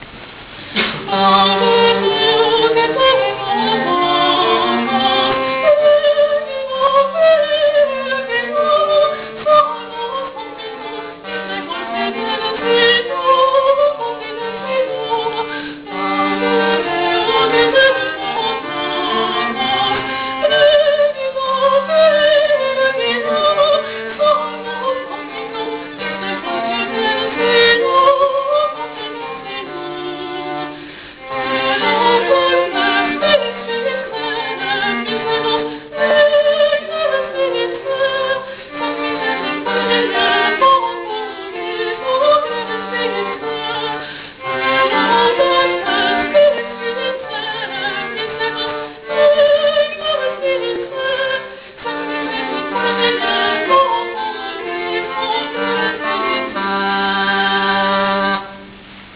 Vernisáž 980108
V další části vystoupil soubor Ars Cameralis s ukázkou sedmi dobových skladeb.